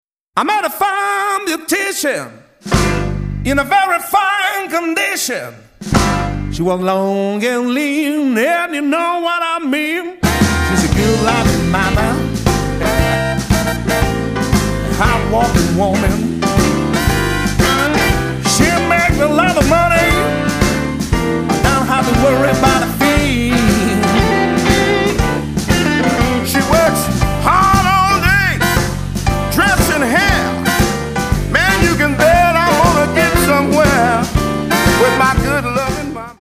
vocal, guitar
organ, piano
harp
bass
drums